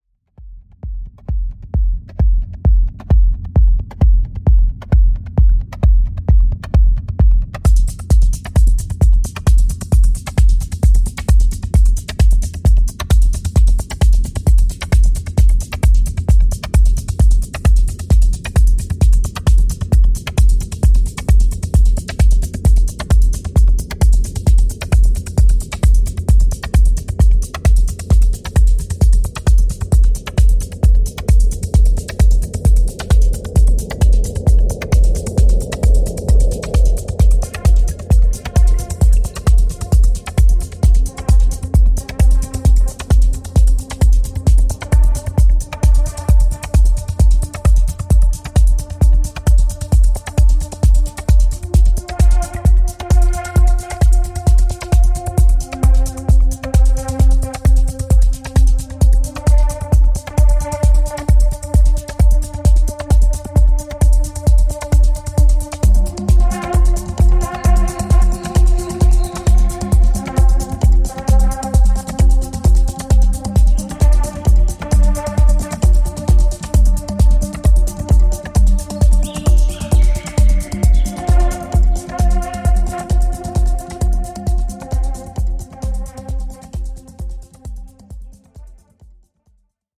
This Song can electrify dance floors!